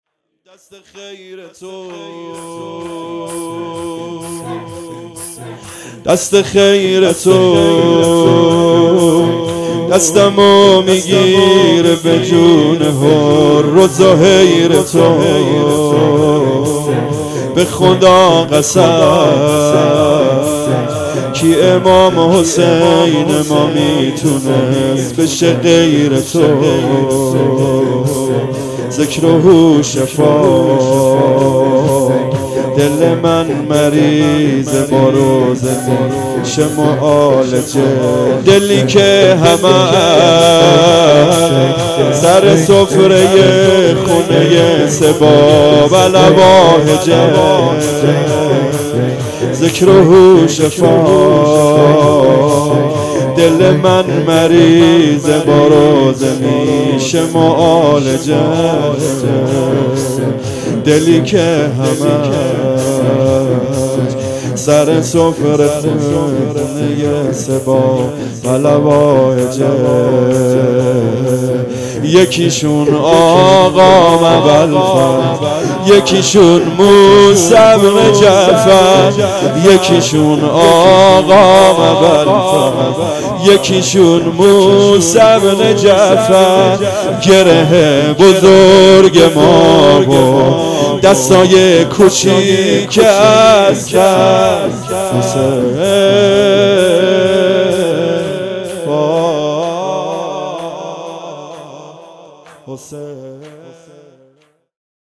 دست خیرتو دستمو میگیره به جون حر و زهیر تو _ شور